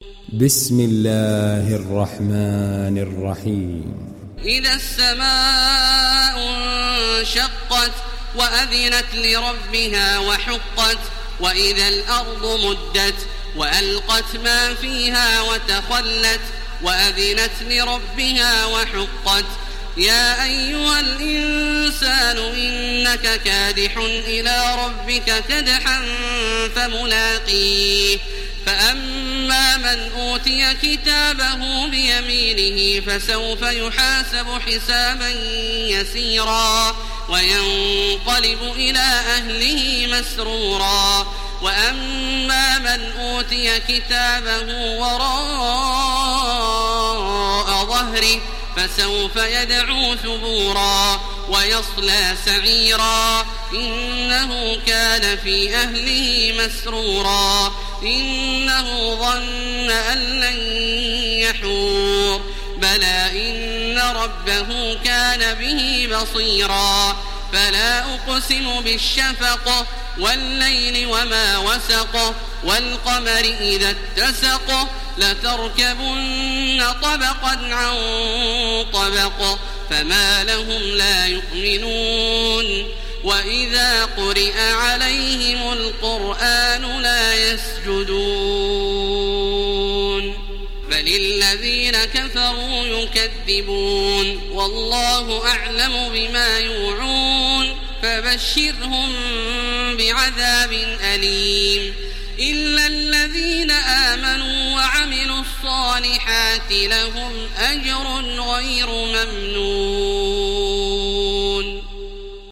تحميل سورة الانشقاق mp3 بصوت تراويح الحرم المكي 1430 برواية حفص عن عاصم, تحميل استماع القرآن الكريم على الجوال mp3 كاملا بروابط مباشرة وسريعة
تحميل سورة الانشقاق تراويح الحرم المكي 1430